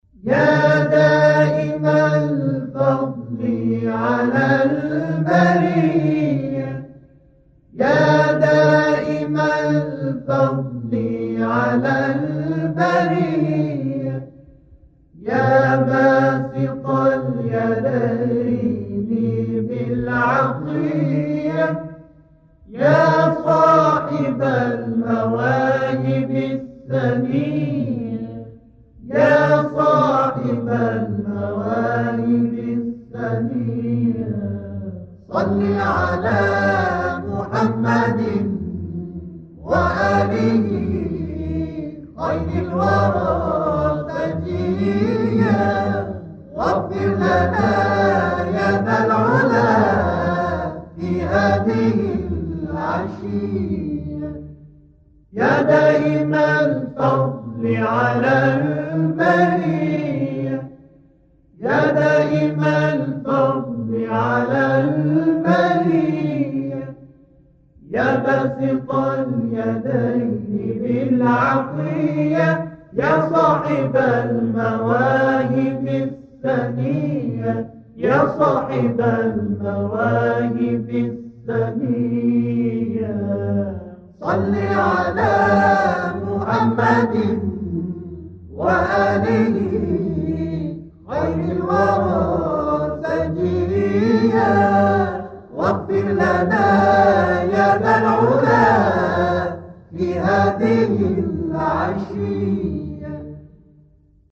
• تواشیح مذهبی, تواشیح اسلامی